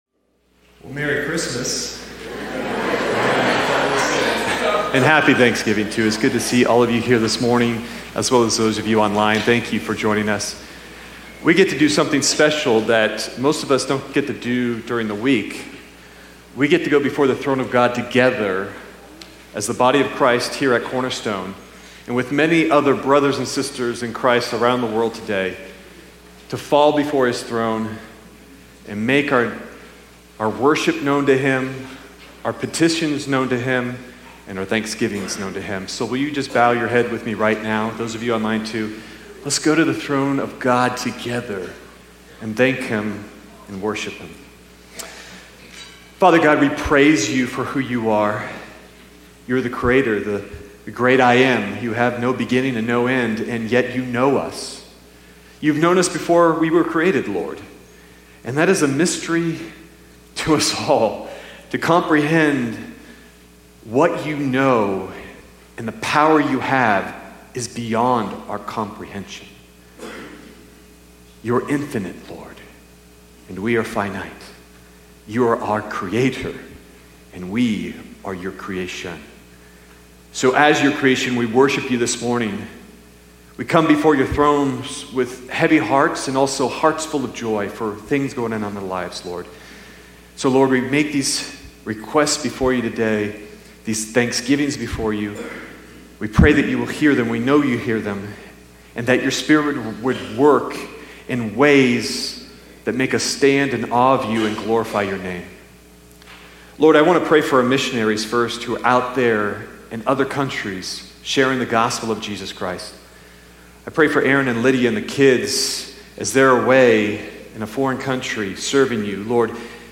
Sermon Detail